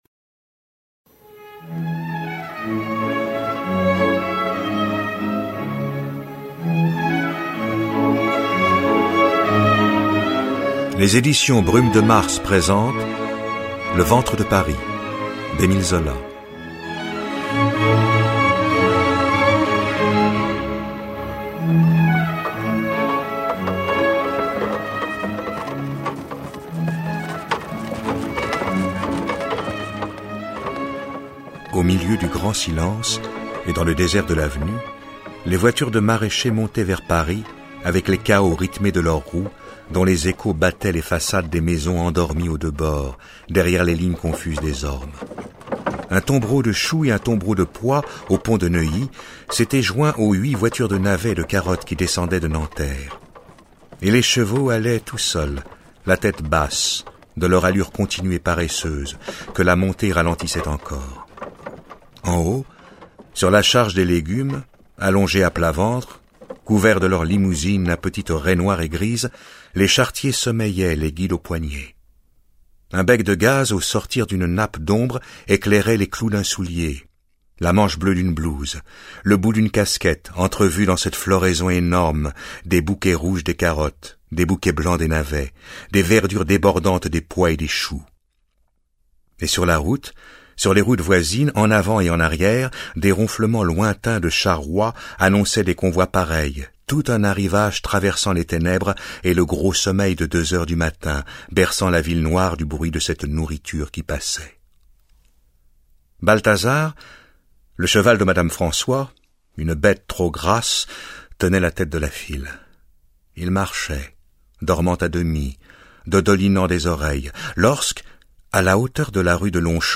Diffusion distribution ebook et livre audio - Catalogue livres numériques
Enregistrement : Studio Scopitone Photo : D.R copyright : Brumes de mars 2014. 12 , 00 € Prix format CD : 25,20 € Ce livre est accessible aux handicaps Voir les informations d'accessibilité